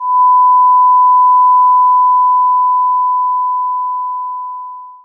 ace_earringing_weak.wav